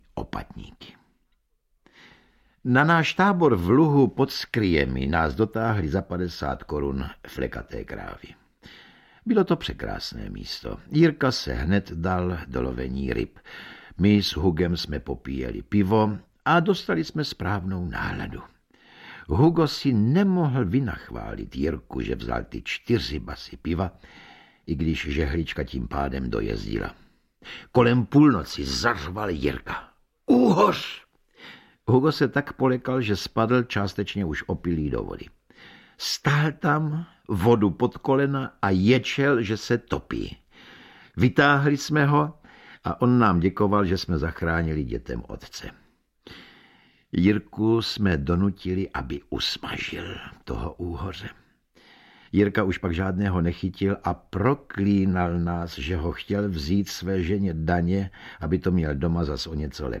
Audiobook
Read: Josef Somr